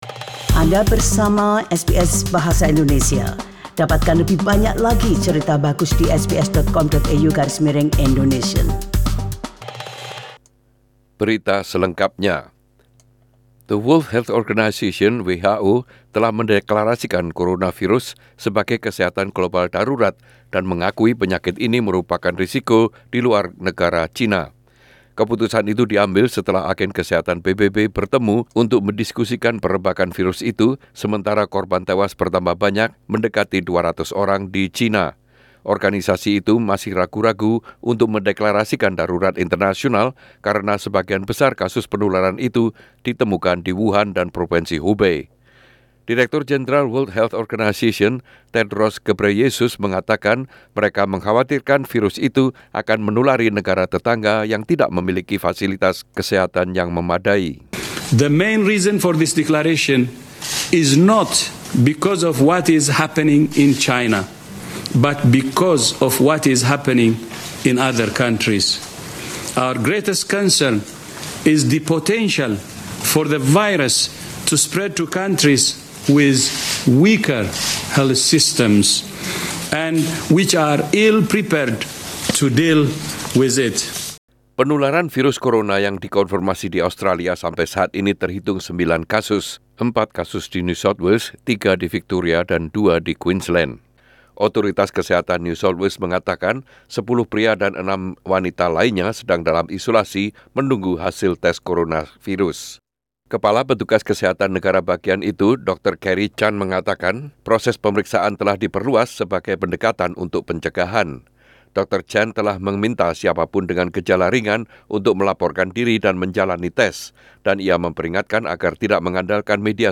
SBS Radio News in Indonesian - 31 Jan 2020